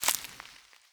harvest_1.wav